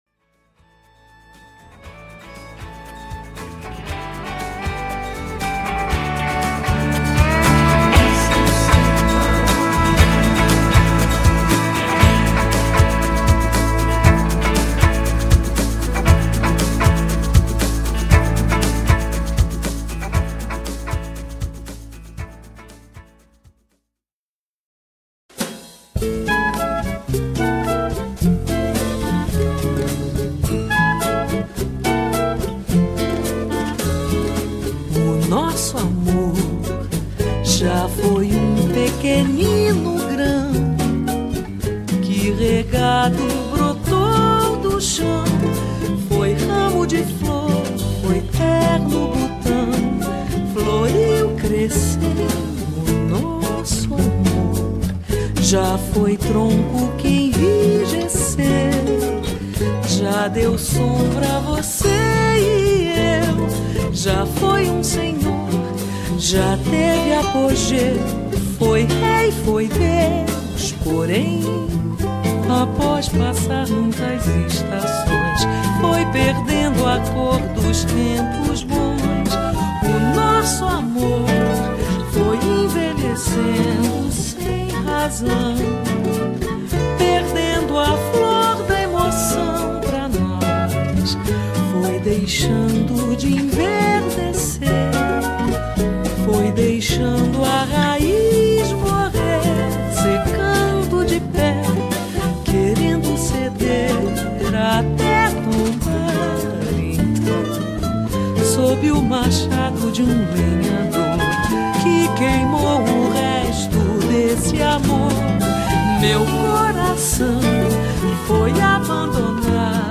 bandolinista